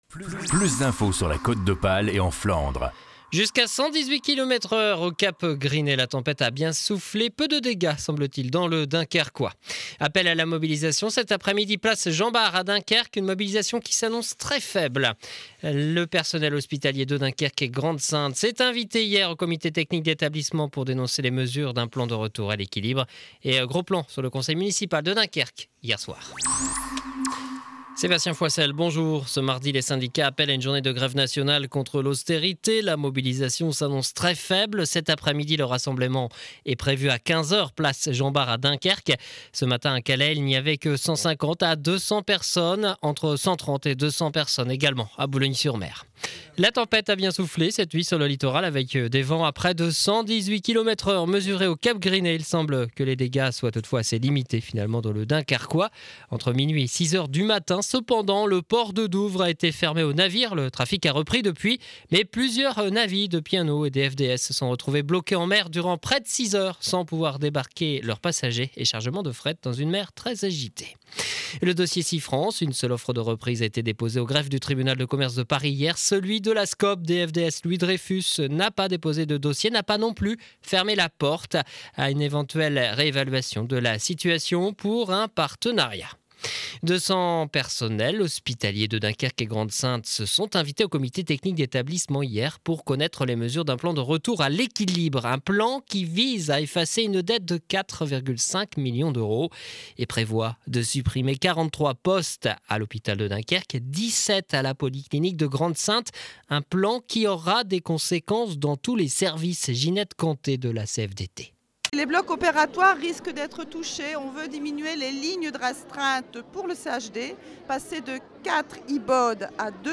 journal du jeudi 15 decembre de 12h à Dunkerque